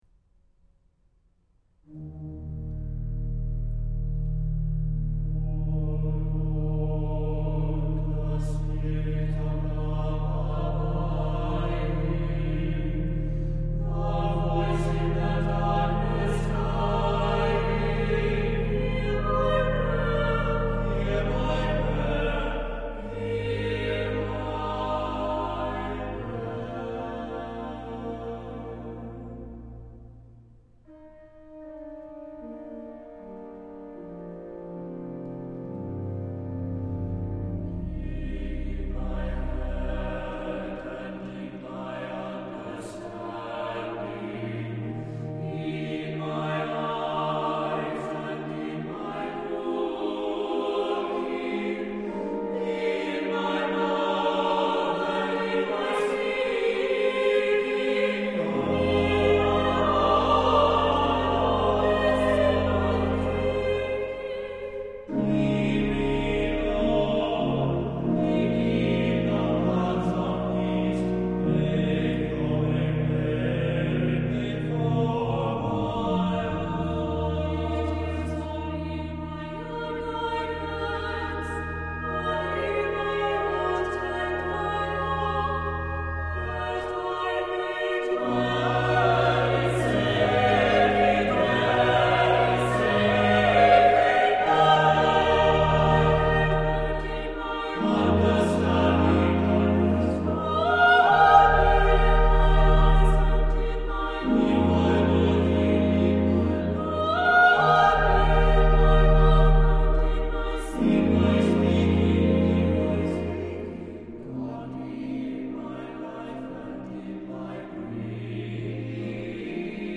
for SATB Choir and Organ or Piano (2004)